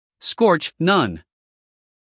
英音/ skɔːtʃ / 美音/ skɔːrtʃ /